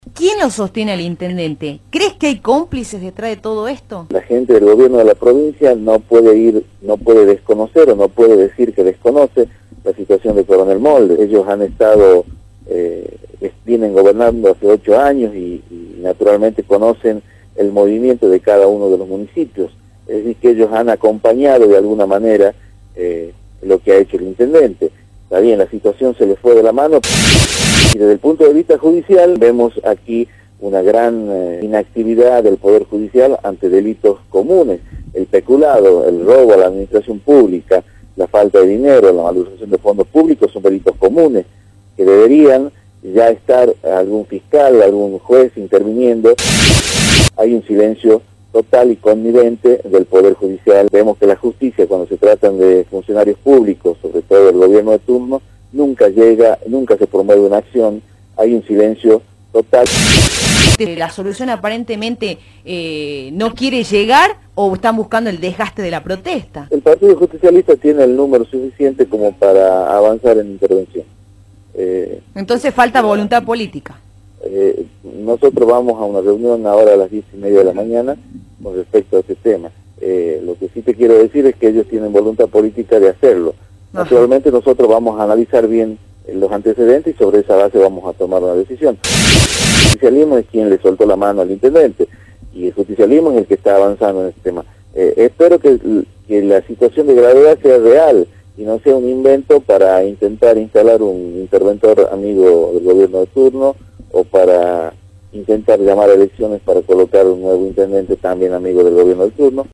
Así lo manifestó el diputado provincial de la Unión Cívica Radical Humberto Vázquez, antes explicó: “El gobierno de la provincia no puede desconocer la situación en Coronel Moldes. Ellos vienen gobernado hace 8 años y, naturalmente, conocen el movimiento de cada uno de los municipios, es decir, que han acompañado de alguna manera lo que ha hecho el intendente, y hoy la situación se les fue de la mano”.